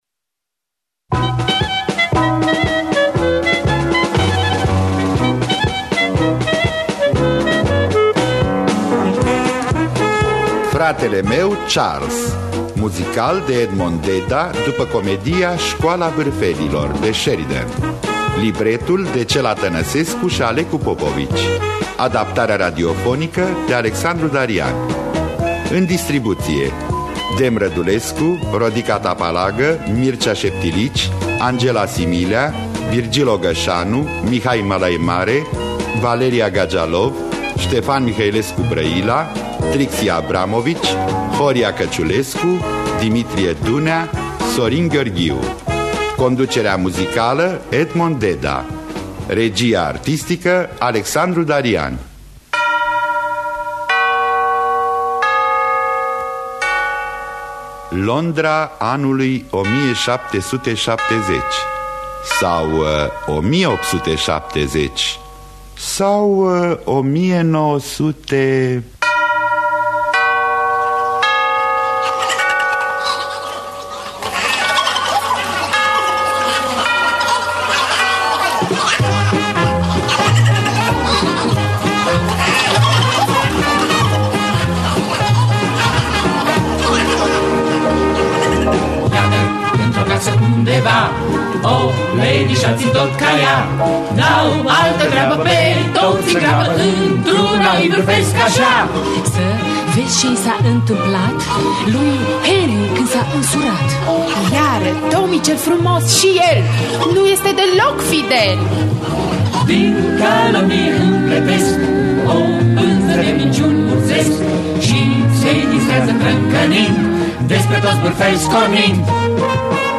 Muzical
Adaptarea radiofonică